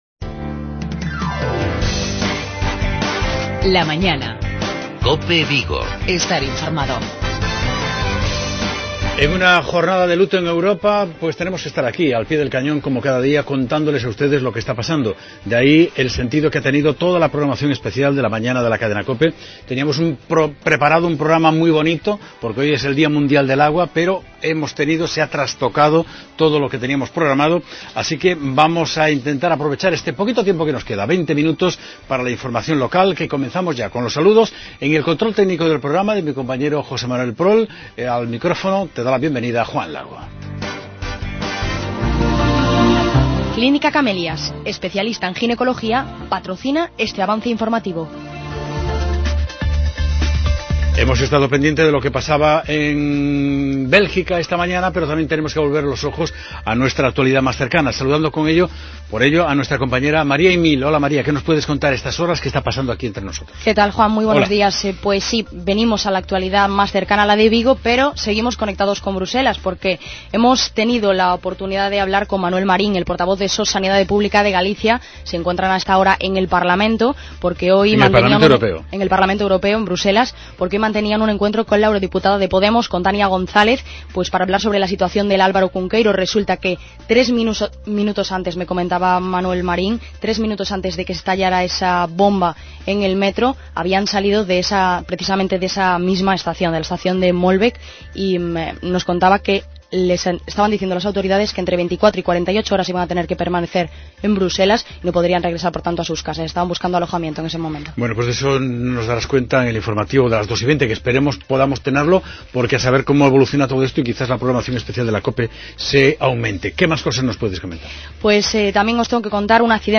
Hoy contamos con un formato reducido de programa, debido a los atentados terroristas de Bruselas. Empezamos con una entrevista a Abel Caballero, alcalde de Vigo. A continuación, para cerrar el programa destacamos que hoy es el Día Mundial del Agua.